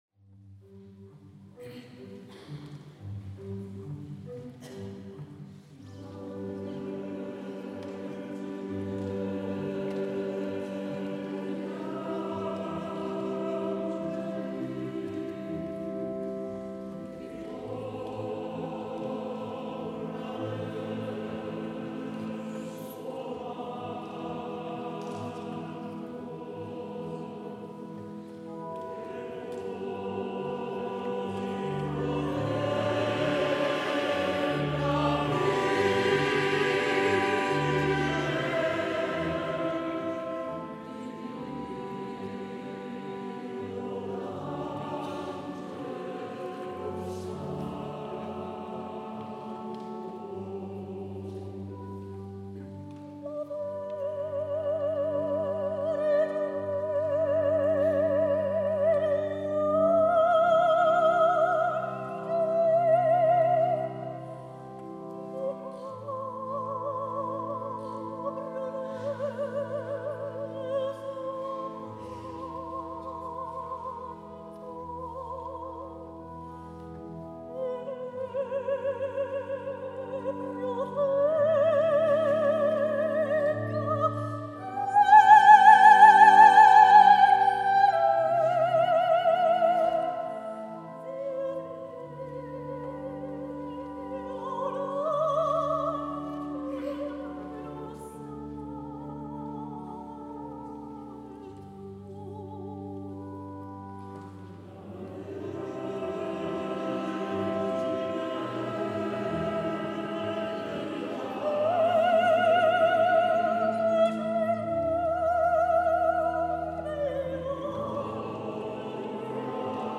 S. Gaudenzio church choir Gambolo' (PV) Italy
22 dicembre 2025 - Concerto di Natale
audio del concerto